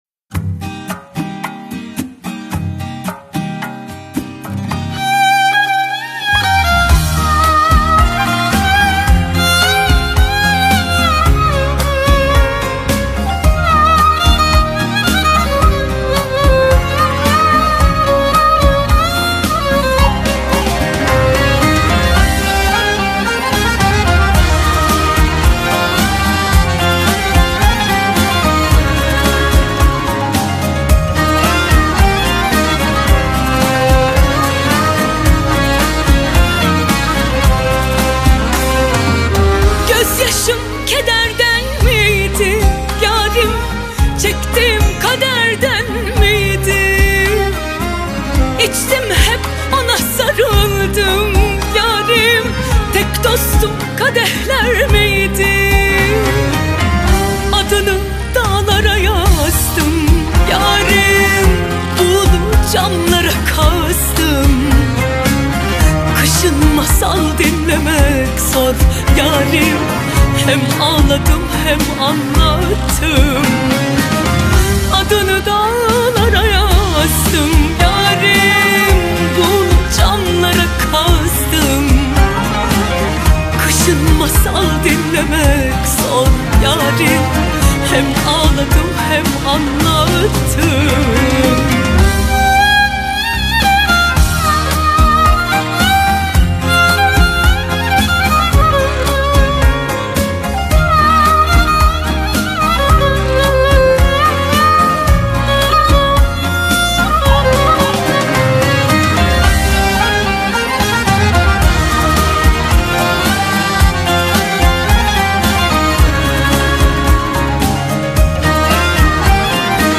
Turkish Pop, Fantazi Music, Arabesque Pop